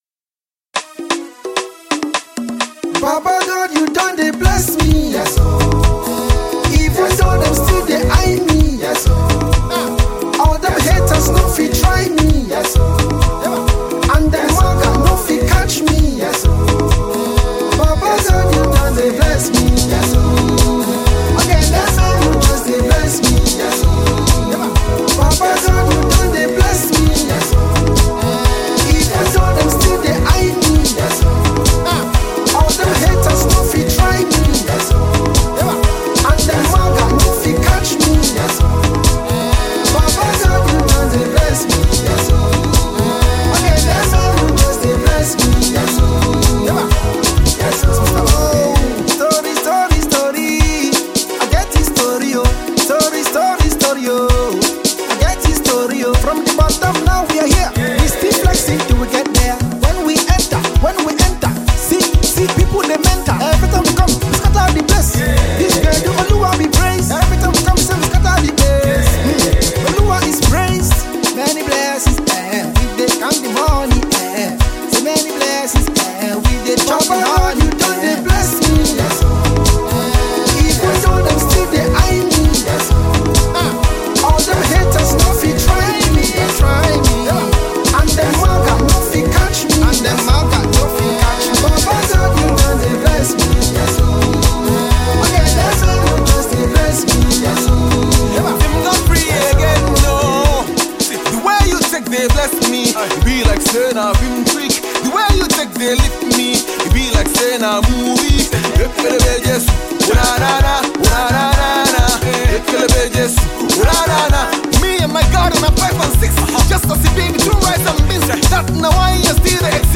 heavy African tune
‘easy-to-sing-along’ song